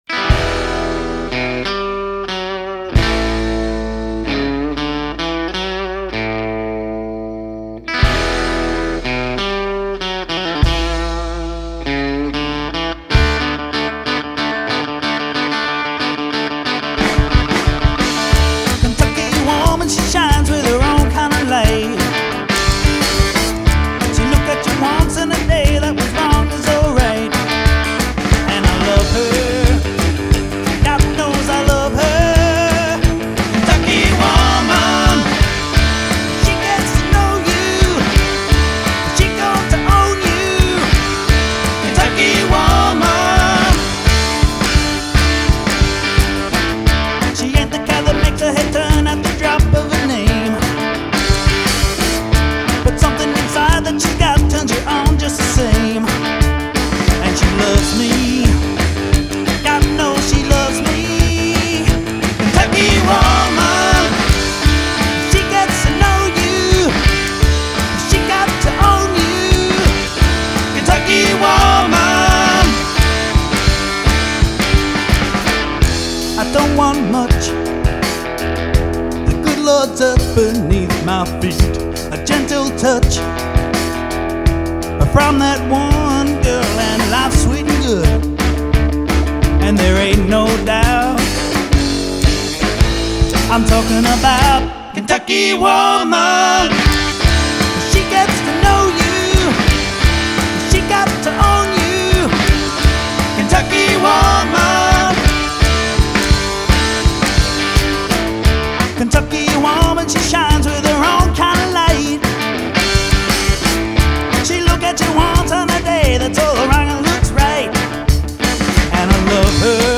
Guitar, Lead Vocal
DBL Bass
Drums
Recorded at Central Sound